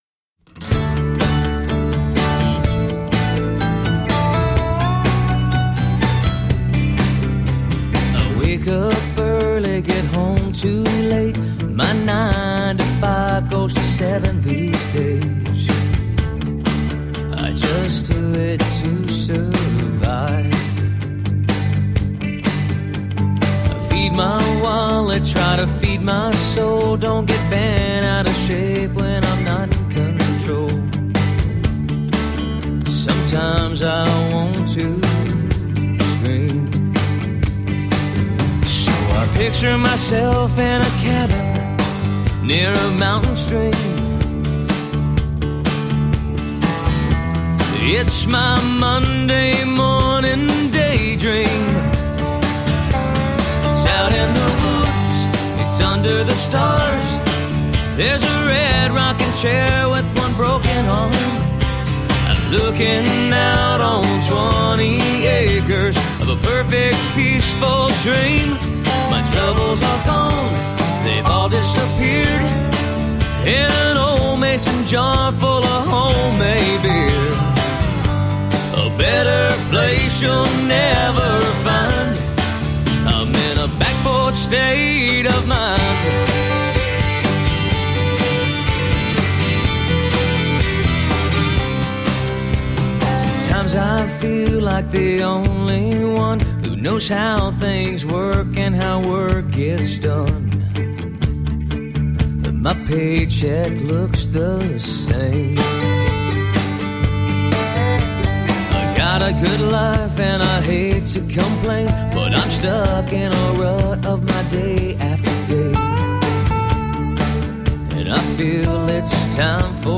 Tagged as: Alt Rock, Folk-Rock, Blues, Country